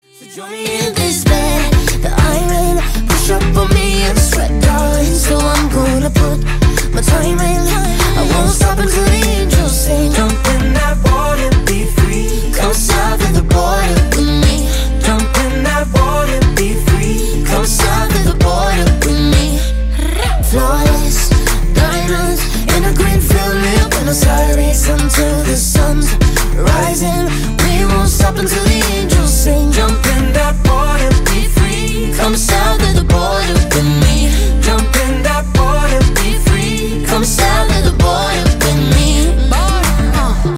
Kategorie Instrumentalny